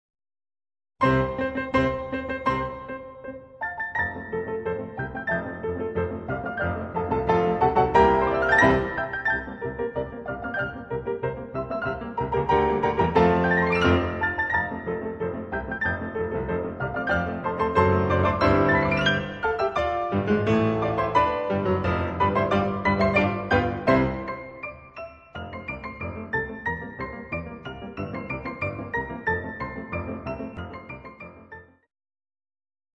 Walzer, Polkas und Märsche
Transkriptionen für Klavier zu vier Händen